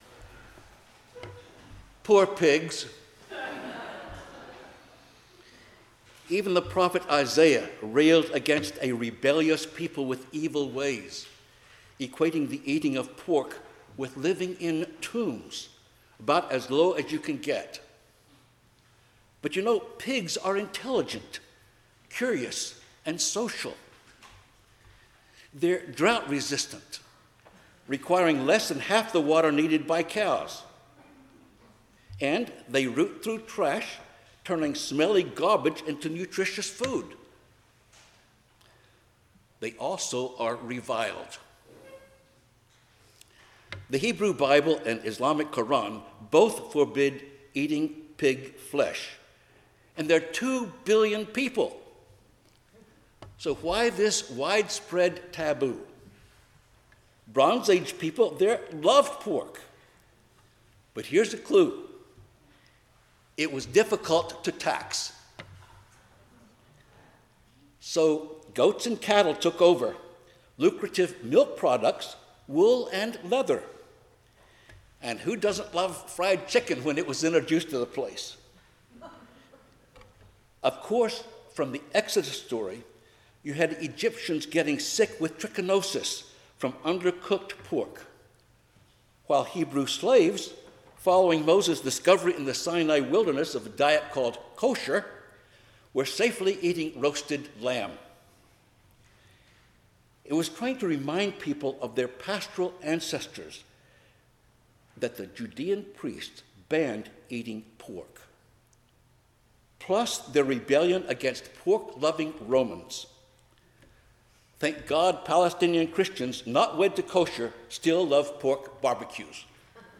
Passage: Isaiah 65:1-9, Psalm 22:18-27, Galatians 3:23-29, Luke 8:26-39 Service Type: 10:00 am Service